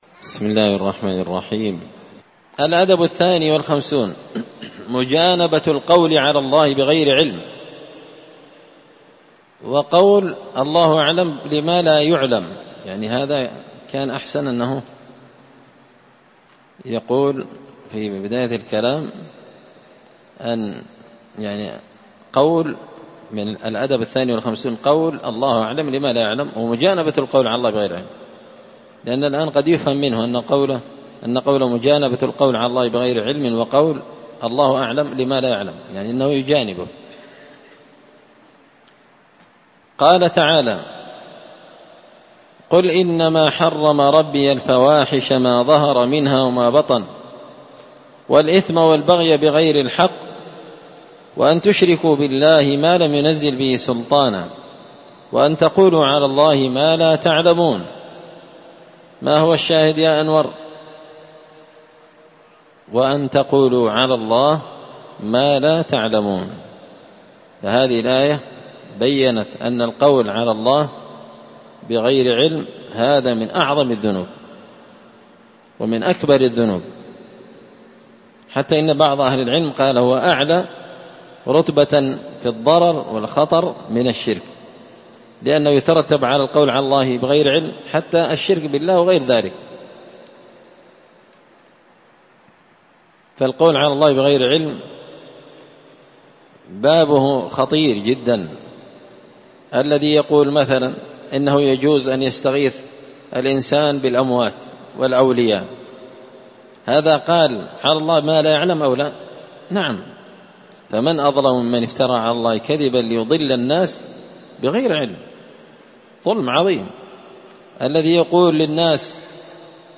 الدرس الستون (60) الأدب الثاني والخمسون مجانبة القول على الله بغير علم